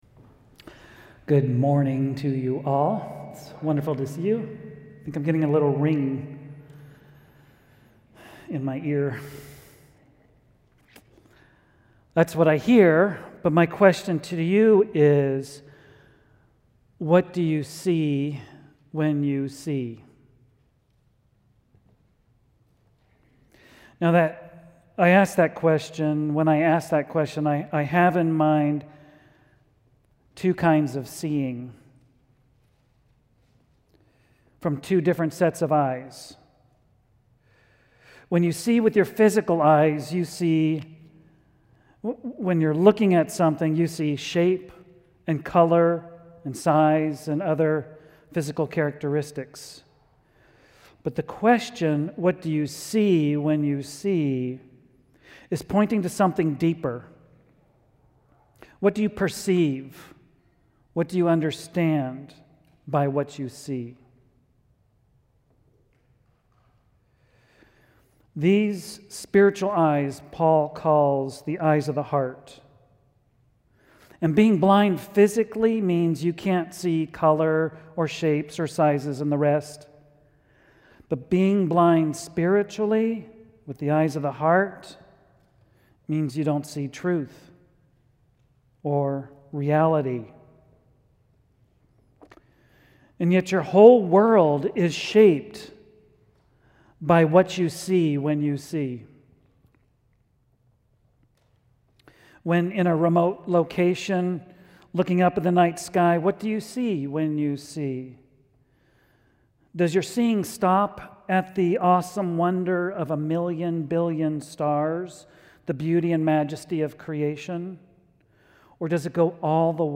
A message from the series "BETTER: Jesus Is _____."